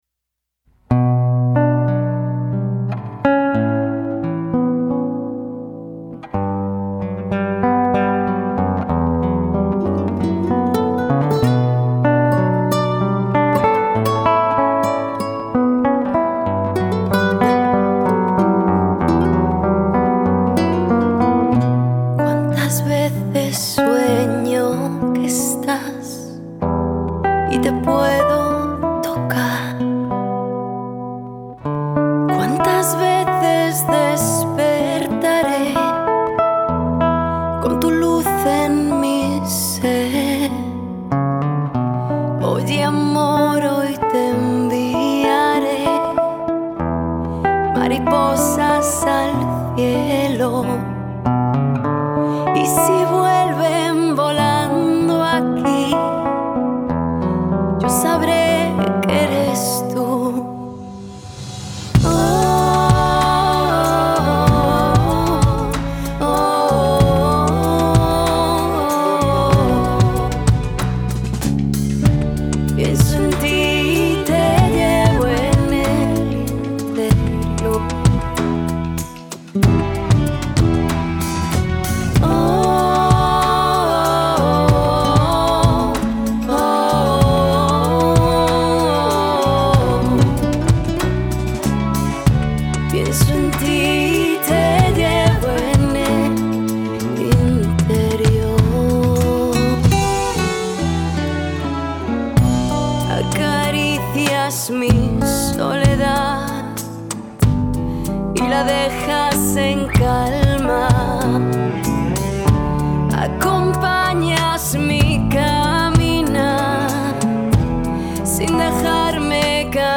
Prosto ze studia z Hiszpanii